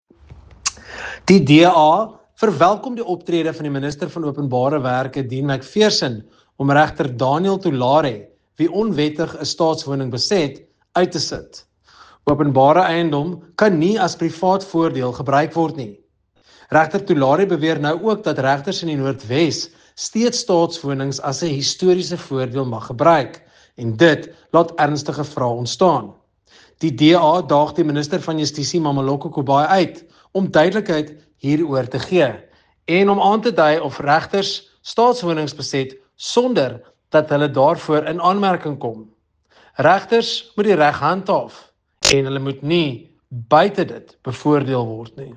Attention Broadcasters: Soundbites in